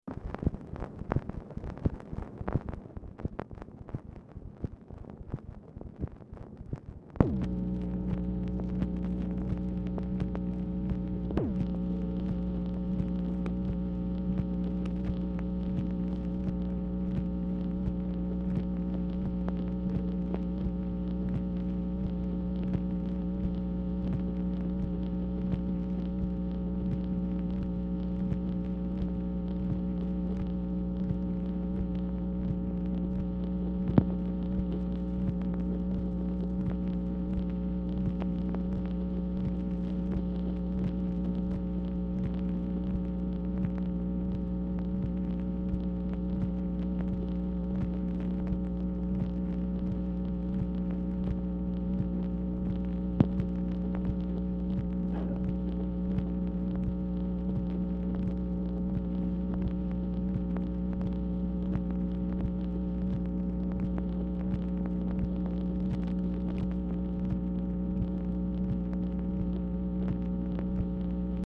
Telephone conversation # 11039, sound recording, MACHINE NOISE, 11/14/1966, time unknown | Discover LBJ
Format Dictation belt
Location Of Speaker 1 LBJ Ranch, near Stonewall, Texas